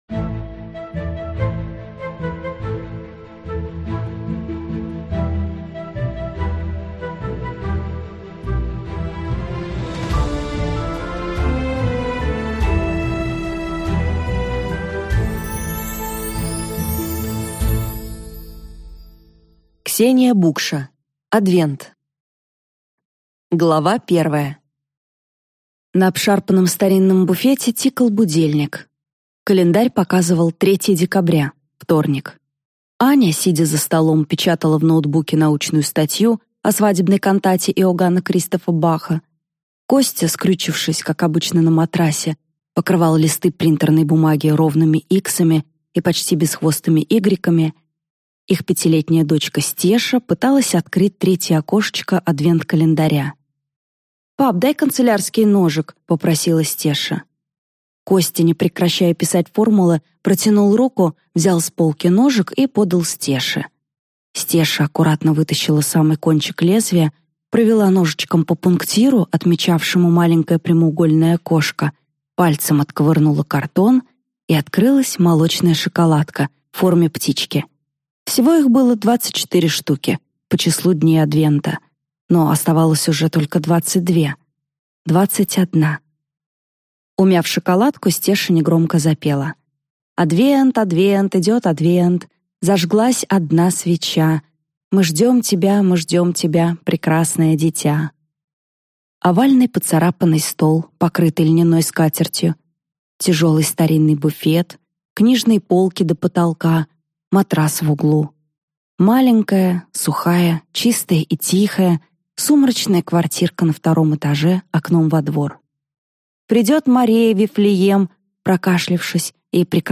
Аудиокнига Адвент | Библиотека аудиокниг